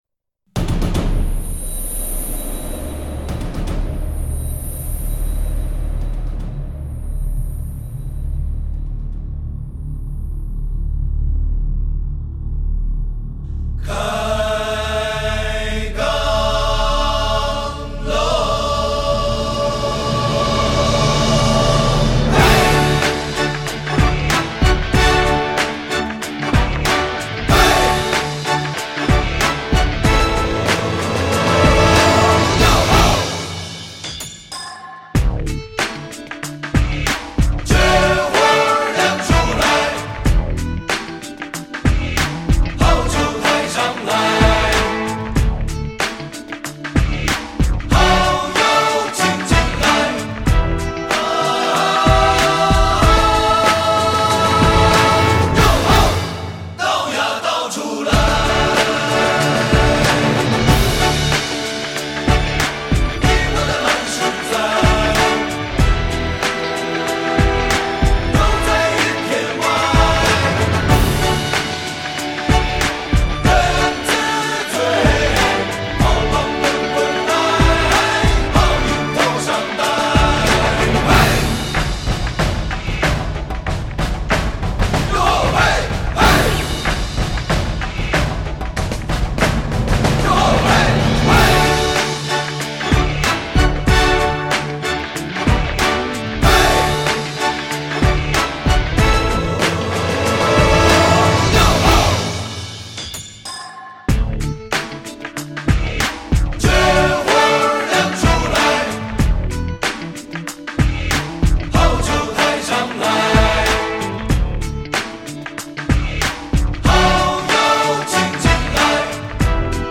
伴奏：